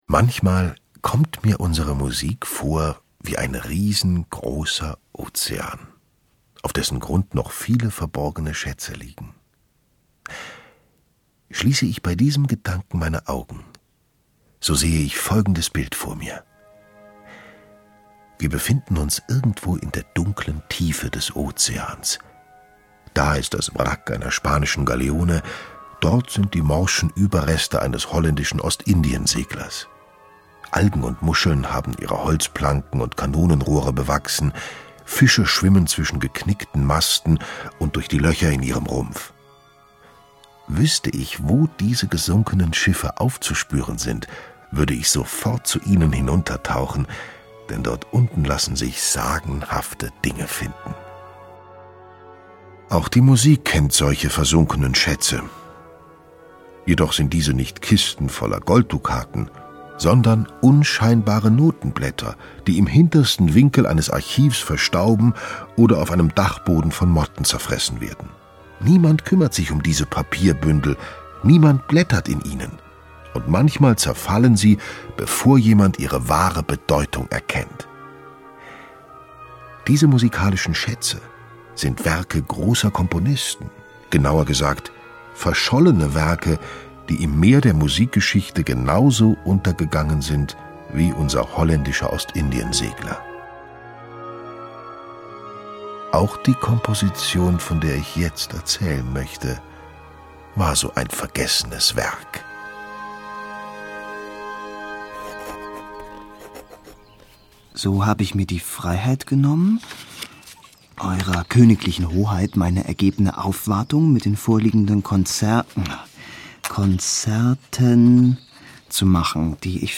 Schlagworte Bach • Bach, Johann Sebastian; Kindersachbuch/Jugendsachbuch • Bach, Johann S.; Kindersachbuch/Jugendsachbuch • Bach, Johann S.; Kindersachbuch/Jugendsachbuch (Audio-CDs) • Hörbuch für Kinder/Jugendliche • Hörbuch für Kinder/Jugendliche (Audio-CD) • Hörbuch; Lesung für Kinder/Jugendliche • Klassik • Klassische Musik